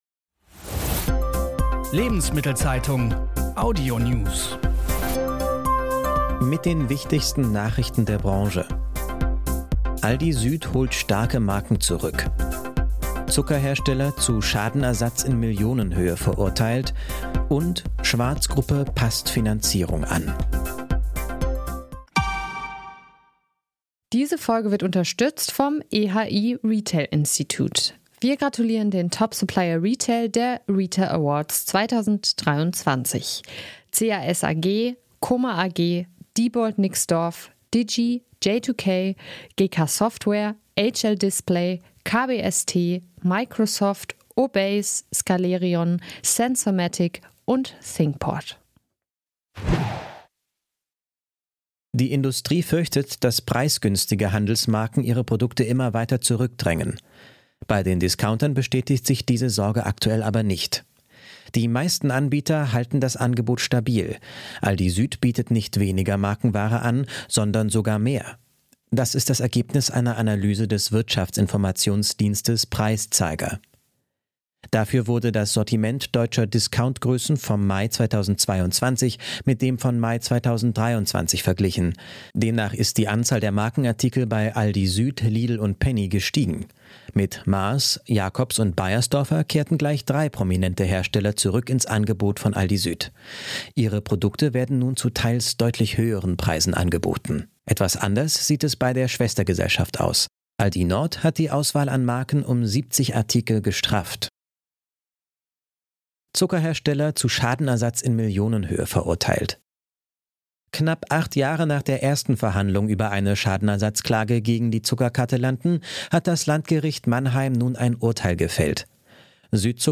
Wirtschaft , Nachrichten